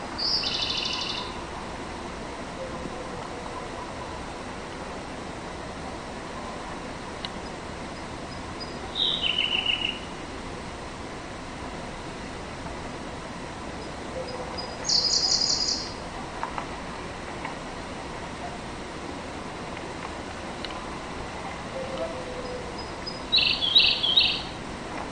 Синий соловей (окр. Введенщины, июнь 2019)
solovey sin.mp3